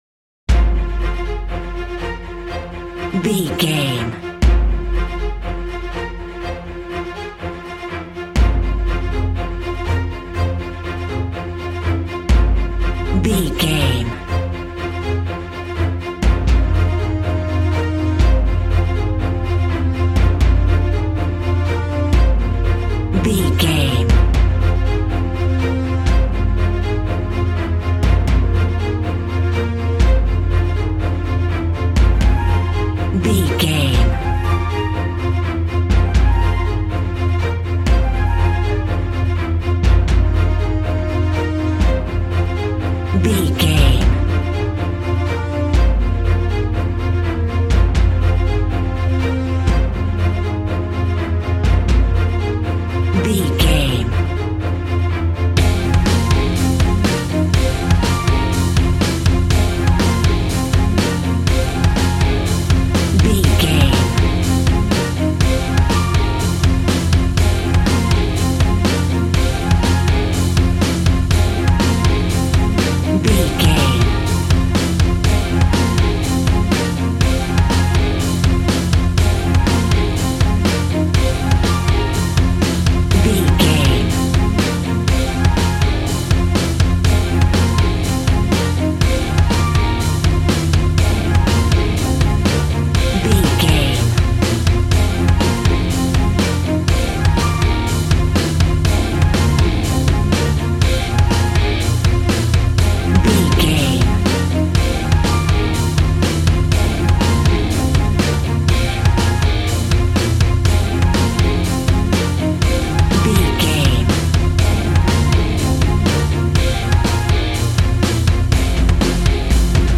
Epic / Action
Fast paced
In-crescendo
Uplifting
Aeolian/Minor
strings
brass
percussion
synthesiser